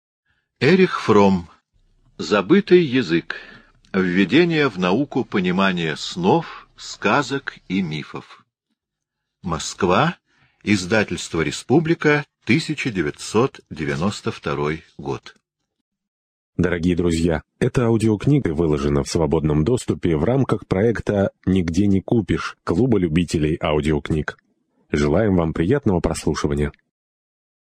Жанр: Speech.